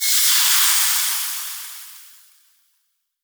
fireball_impact_magic_smoke_02.wav